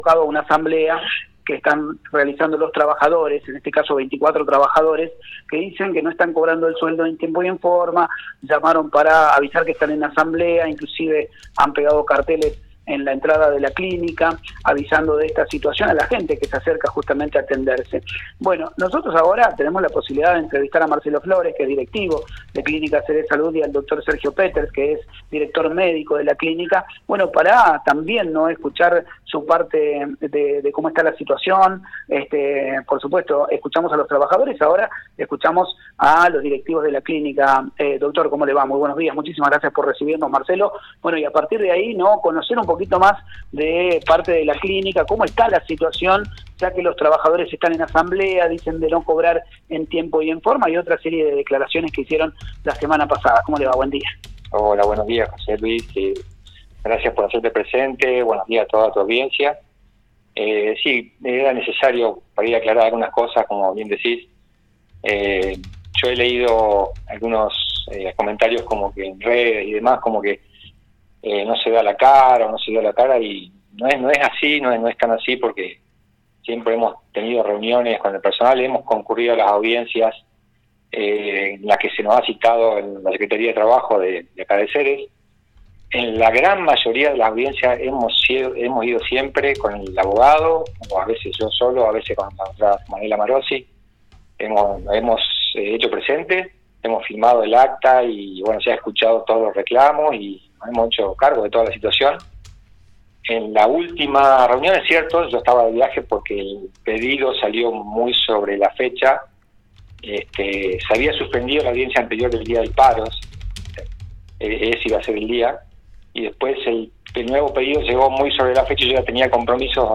Escucha el Móvil en Vivo que realizamos desde la Clínica.